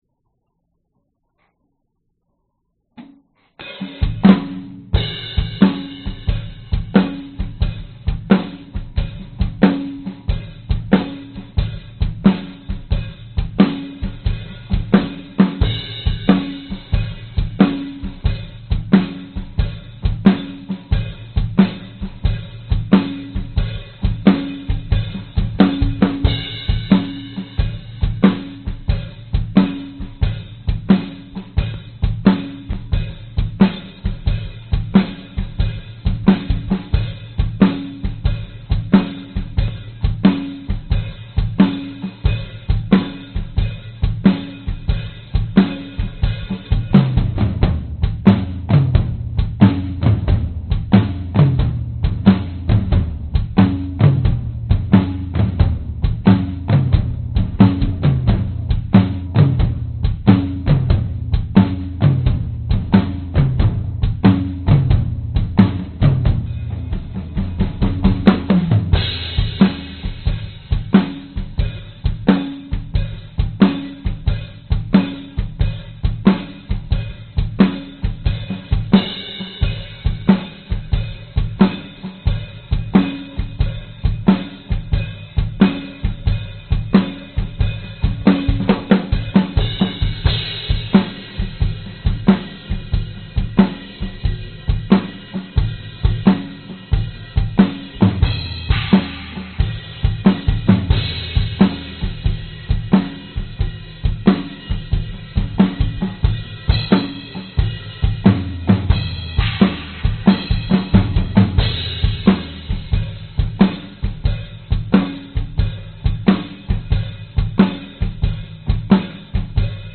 狮子吼
Tag: 生物 尖叫 假的 动物 丛林 恐怖 狮子 愤怒 咆哮 咆哮着 怪物 恐吓 咆哮 可怕的 充满异国情调